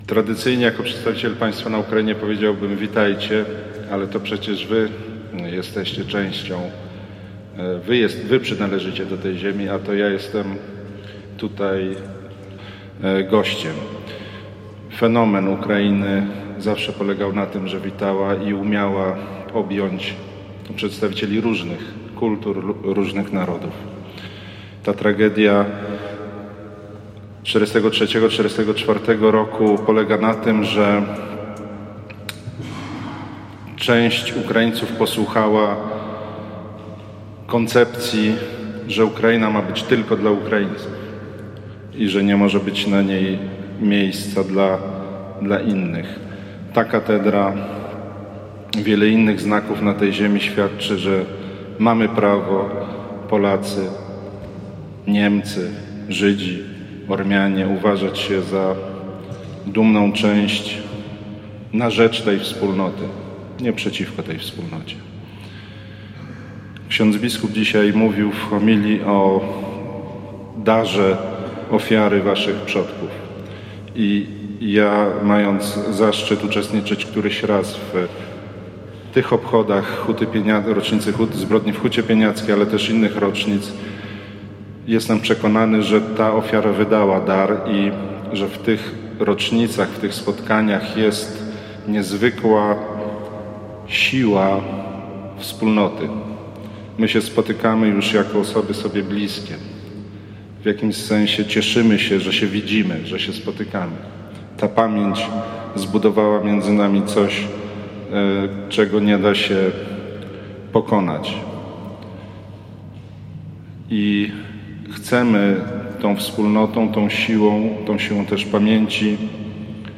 Na Ukrainie rozpoczęły się wieczorem uroczystości 76 rocznicy pogromu Huty Pieniackiej. Pod koniec lutego 1944 roku ukraińscy nacjonaliści wymordowali około tysiąca polskich mieszkańców tej miejscowości.
Posłuchaj wystąpienia ambasadora RP na Ukrainie Bartosza Cichockiego: